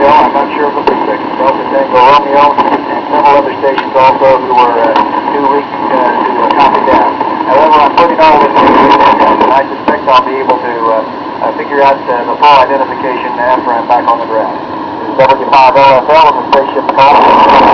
This is a recording of W5LFL, Astronaut Owen Garriott aboard theSpace Shuttle Columbia when he was making the first ever Ham-Astronaut to Hams on Earth two-way radio transmissions.
He was using a specially built two meter hand-held and an antenna mounted against the window.
He is holding a Motorola two meter FM ham radio walkie talkie."When in orbit over land, I could make a CQ, which is a general call, and see who responded," Garriott said.